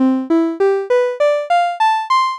scale.wav